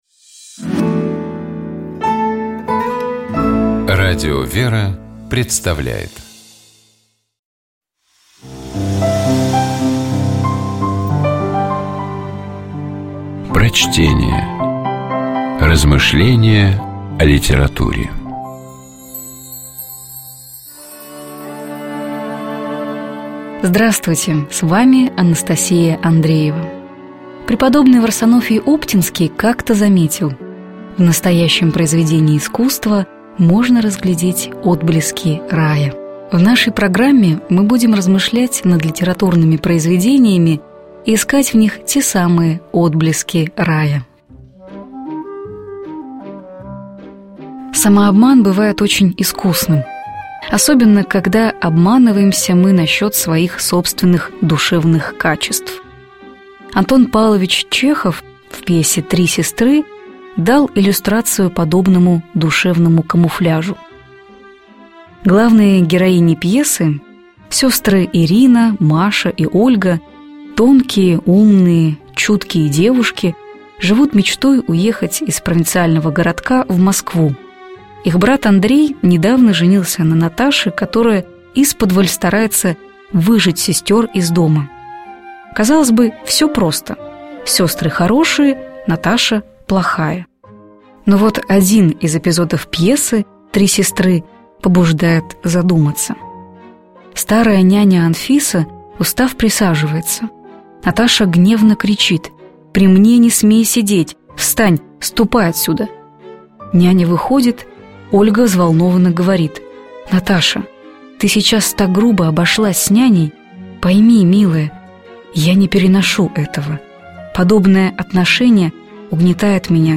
Prochtenie-Chehov-Tri-sestry-Iskusnoe-pritvorstvo.mp3